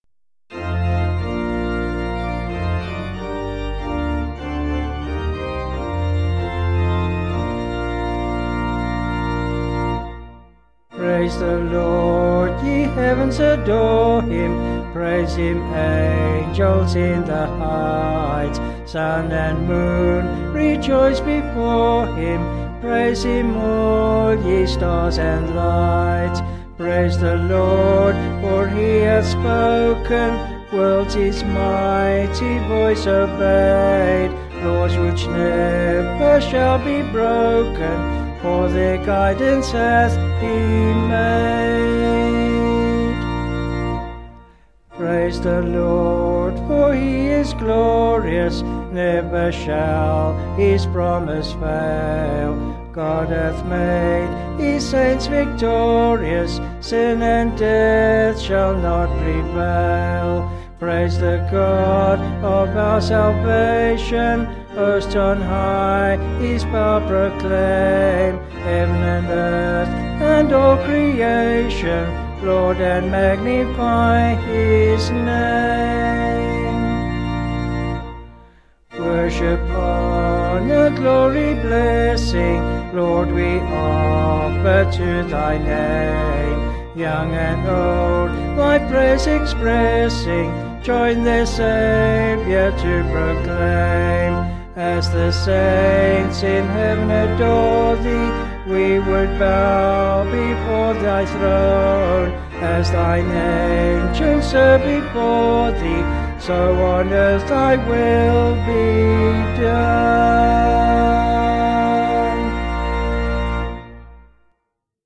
Vocals and Band   264kb Sung Lyrics 1.6mb